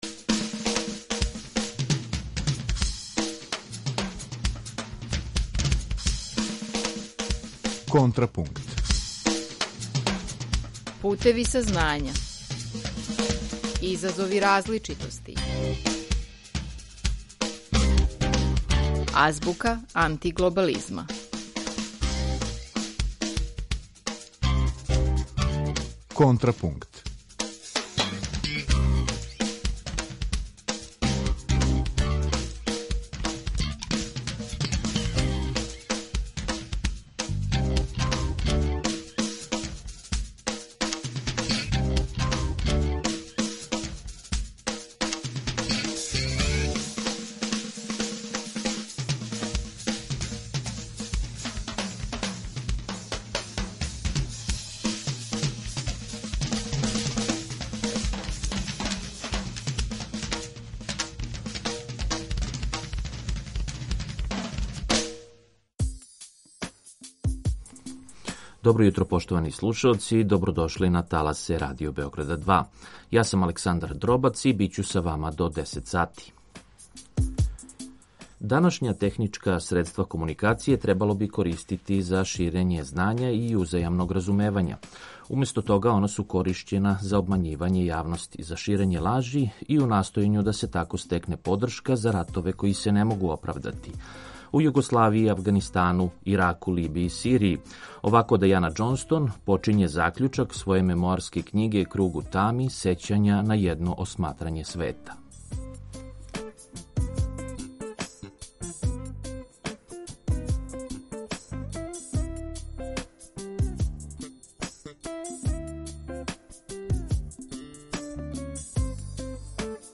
Гост емисије је универзитетски професор социологије и дугогодишњи новинар